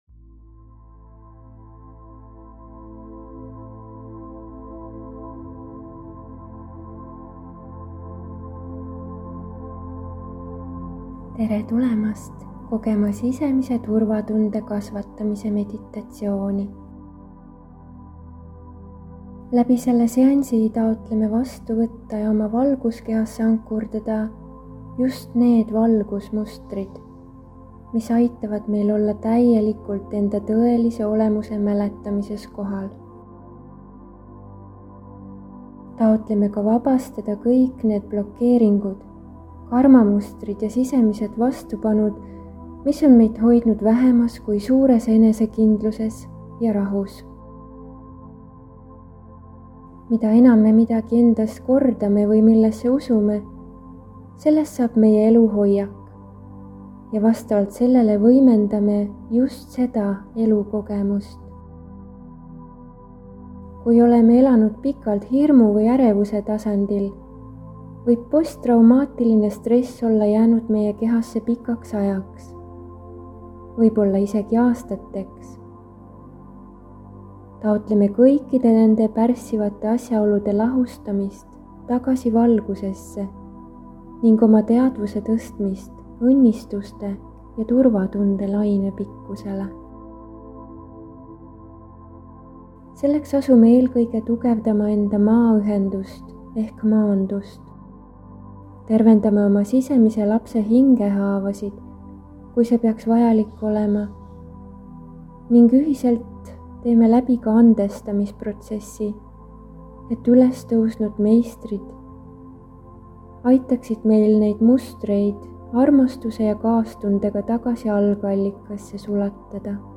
Imposter sündroomi tervenduse meditatsioon